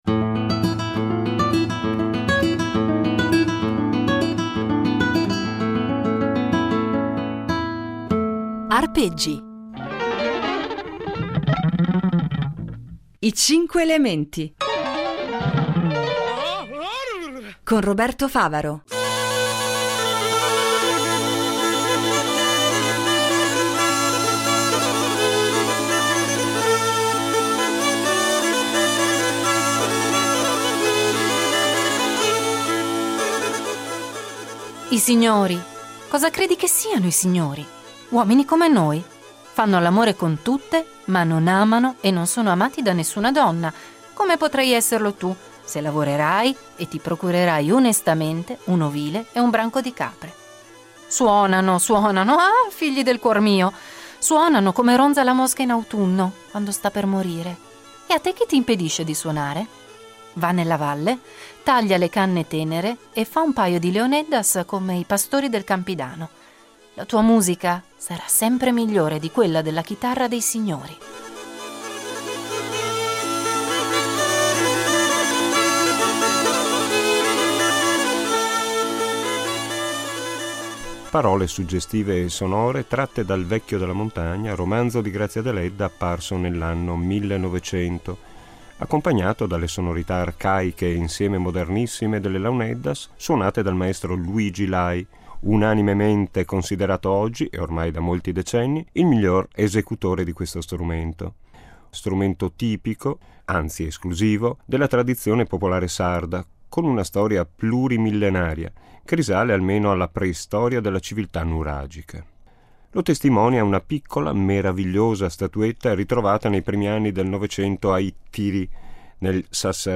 Launeddas (6./10)